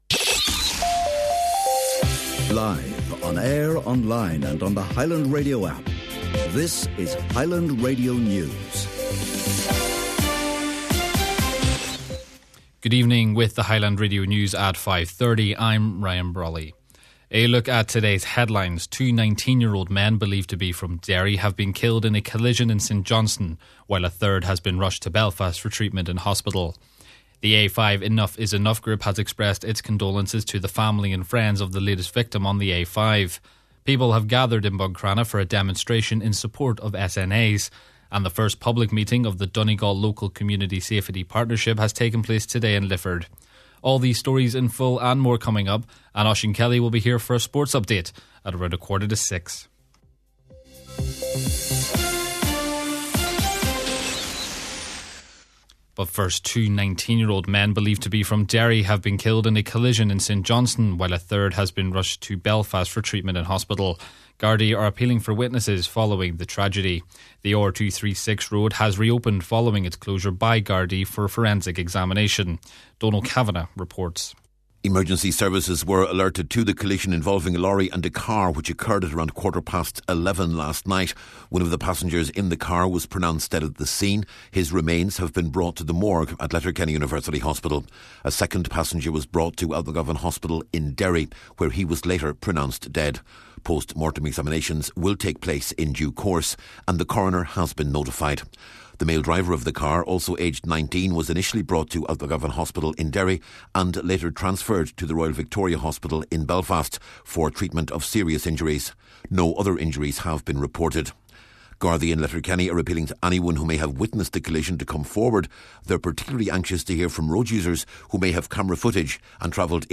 Main Evening News, Sport and Obituary Notices – Wednesday, February 25th: